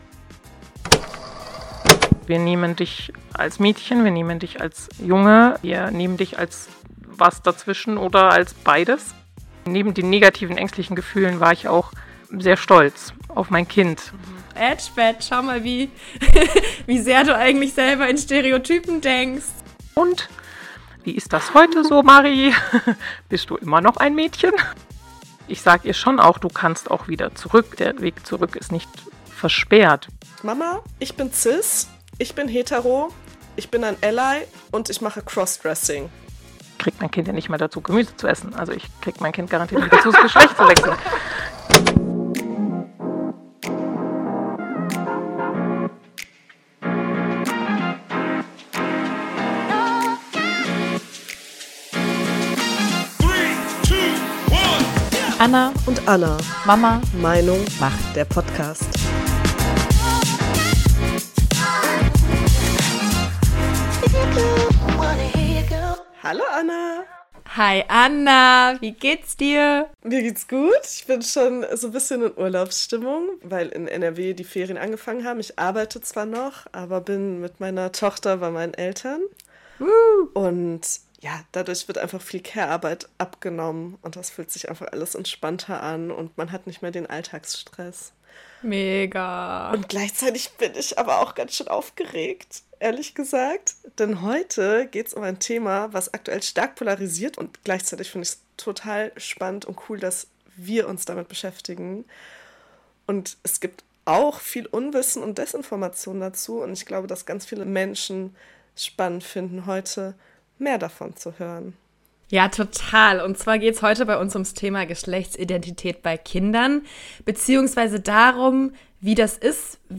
Zum Schluss hört ihr Stimmen von trans Menschen und Angehörigen – mutmachend, witzig, ehrlich.